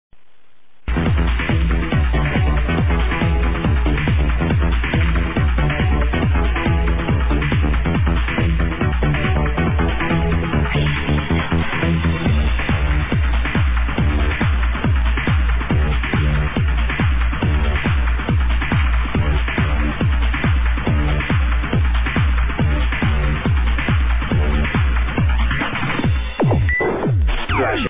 Sorry bout poor qual!